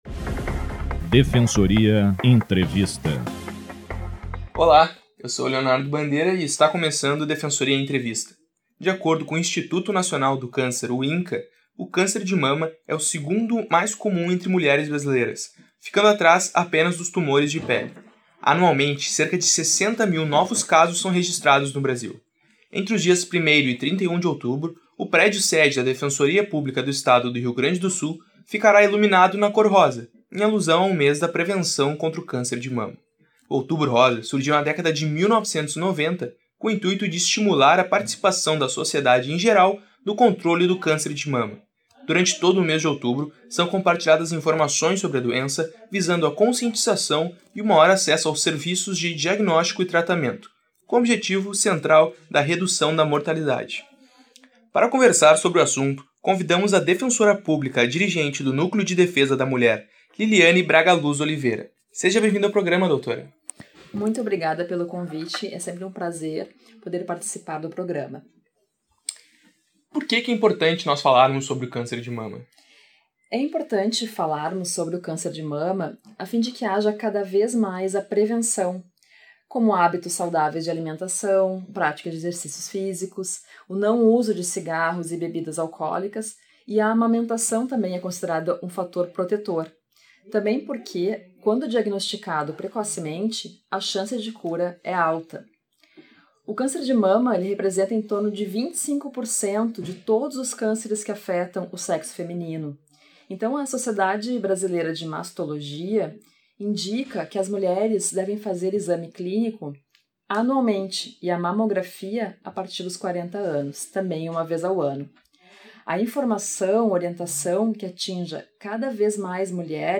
DPE Entrevista